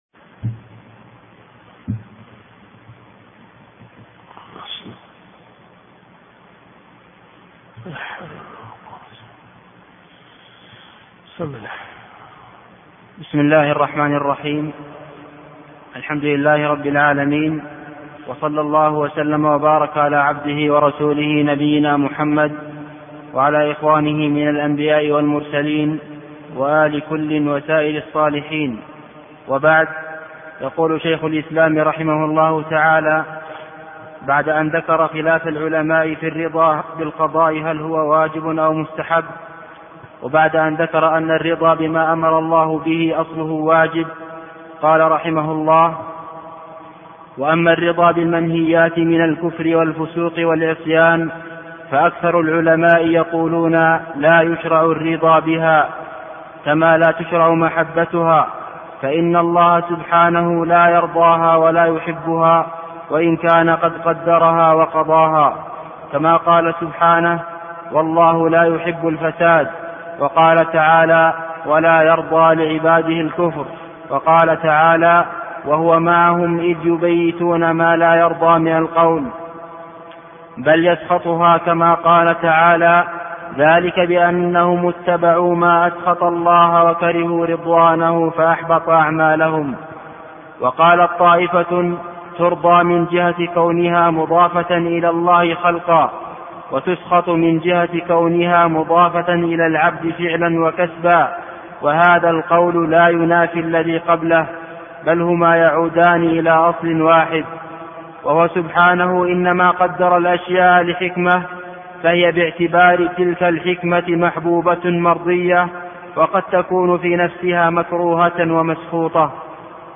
الرئيسية الدورات الشرعية [ قسم السلوك ] > التحفة العراقية لابن تيمية . 1428 .